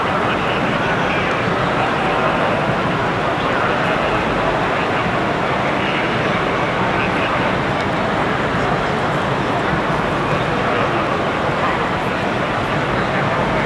rr3-assets/files/.depot/audio/sfx/ambience/ambience_f1_raceday.wav
ambience_f1_raceday.wav